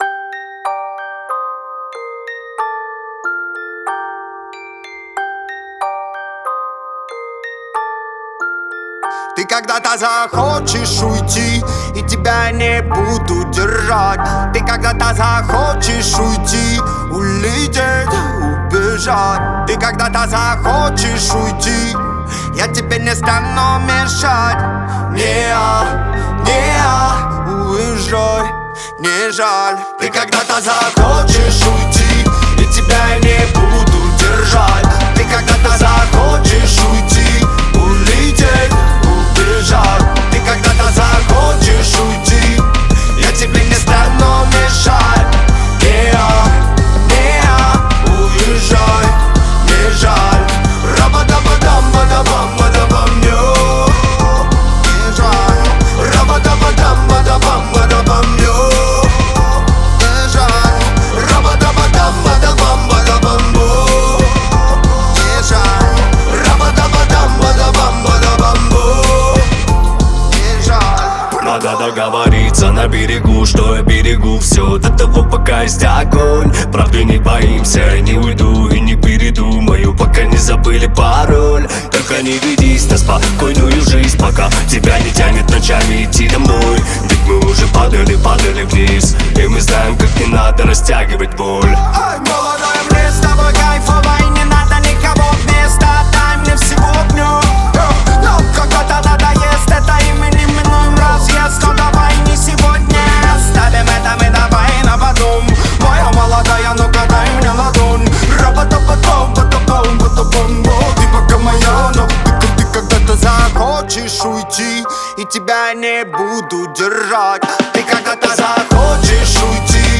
это трек в жанре поп-рэп